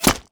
bullet_impact_rock_06.wav